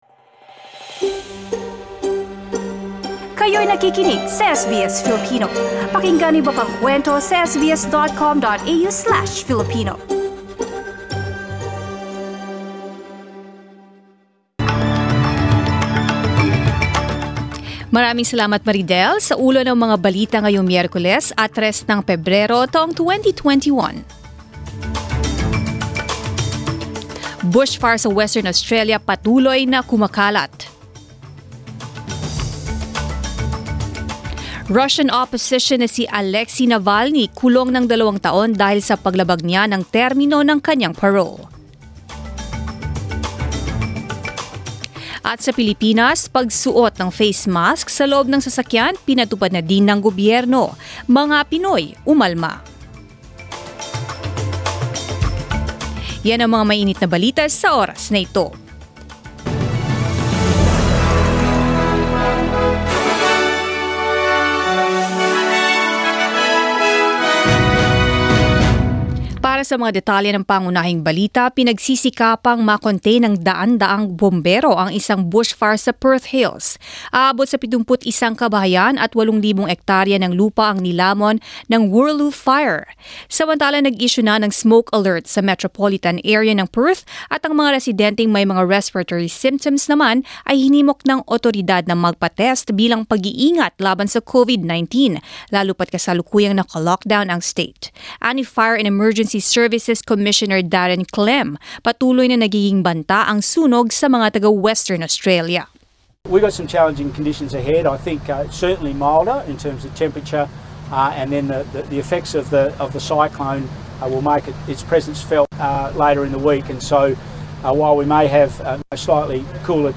Mga balita ngayong ika-3 ng Pebrero